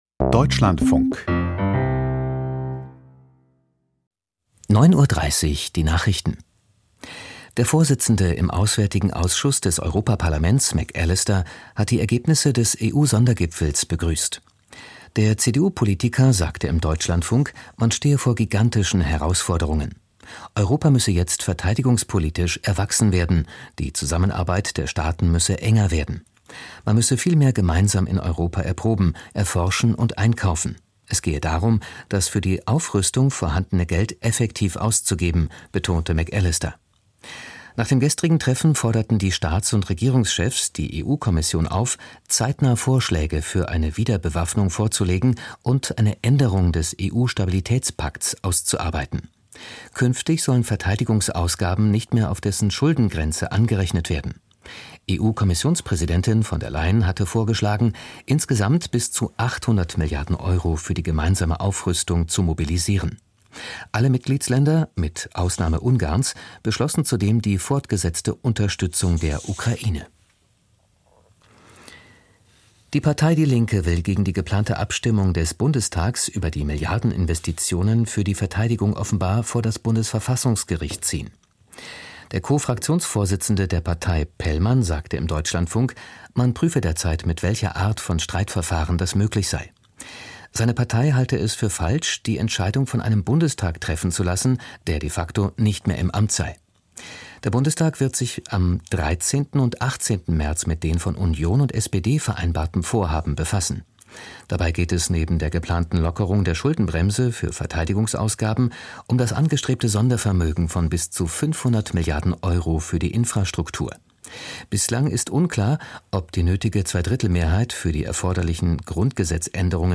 Die Deutschlandfunk-Nachrichten vom 07.03.2025, 09:30 Uhr